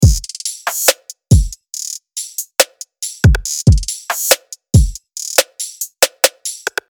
dance_music2.wav